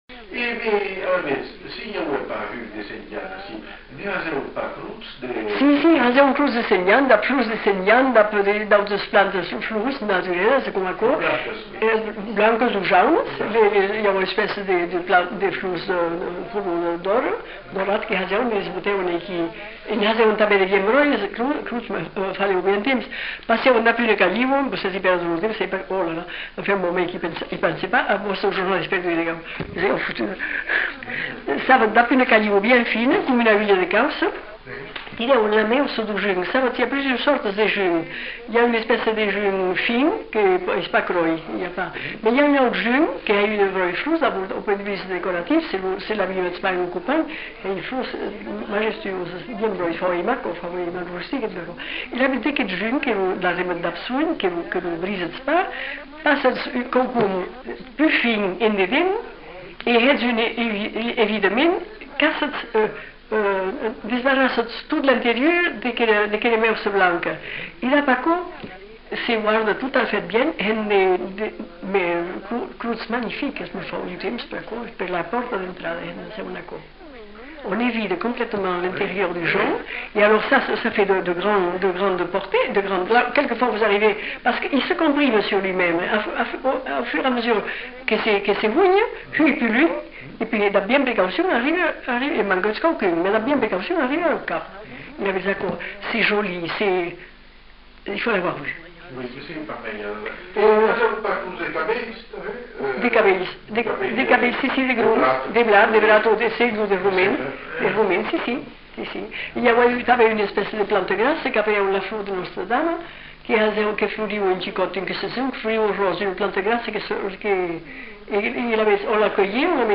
Lieu : Lerm-et-Musset
Genre : témoignage thématique
[enquêtes sonores]